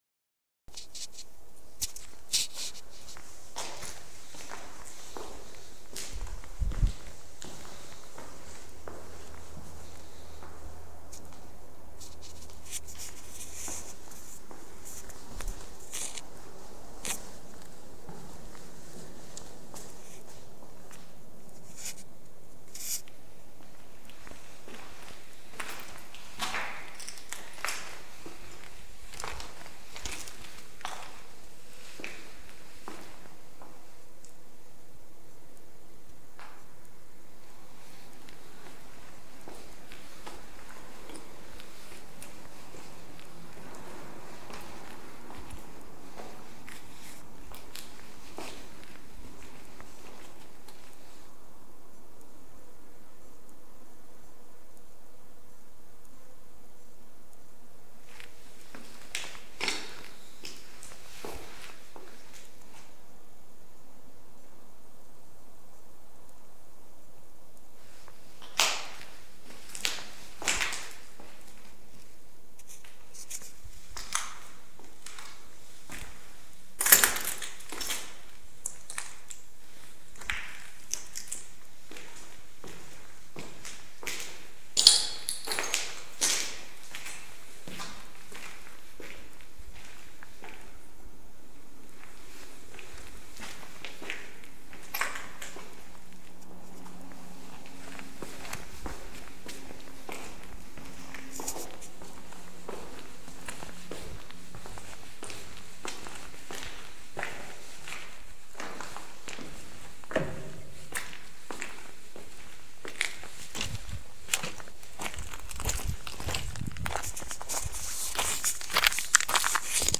Sonidos de pasos que acompañan todo el rato a quien se dió una vuelta por los alrededores de la habitación y de la planta mientras otro se quedaba en la habitación en aislamiento. Se sabe porque él llevaba playeros y se distingue como es su sonido y no había nadie mas andando con zapatos.
Psiquiatrico
pasosacompanan.mp3